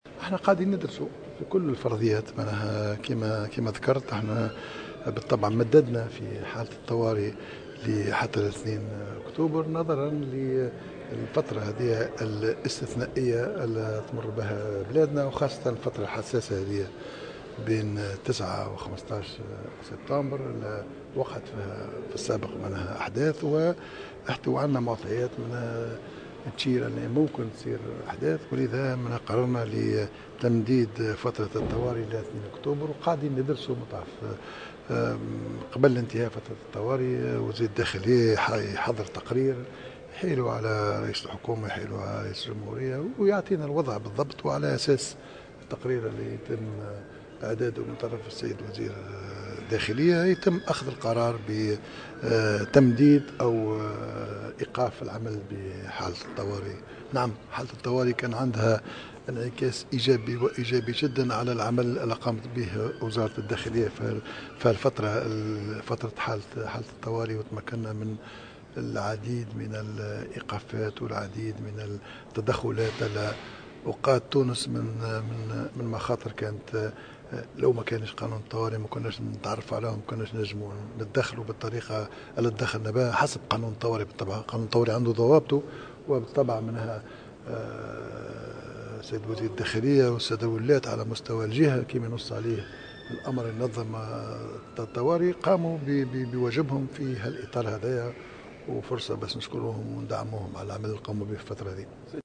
أكد رئيس الحكومة الحبيب الصيد في تصريح لمراسلة الجوهرة أف أم خلال الندوة الدورية الثالثة للولاة لسنة 2015 المنعقدة اليوم الأحد 13 سبتمبر أن الحكومة ستدرس التمديد أو إلغاء العمل بحالة الطوارئ بعد التقرير النهائي الذي سيقدمه وزير الداخلية ناجم الغرسلي عن الوضع الأمني في البلاد.